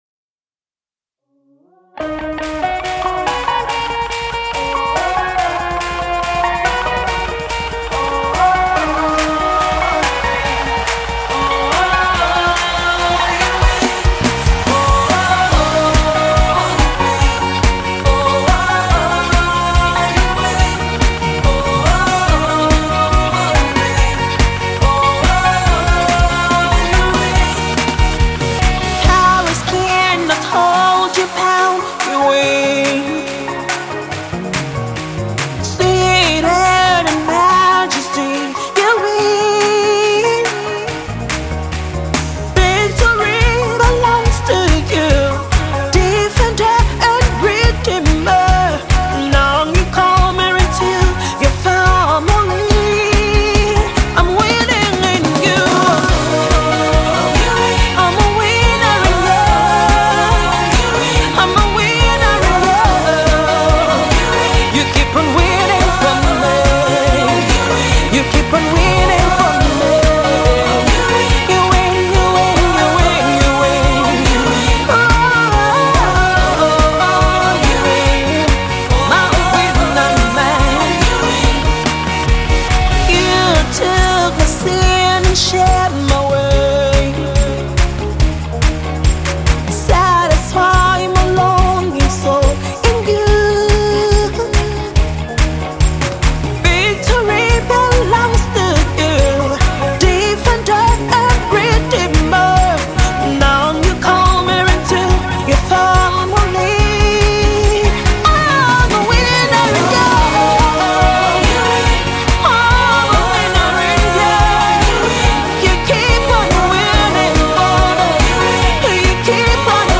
Gospel singer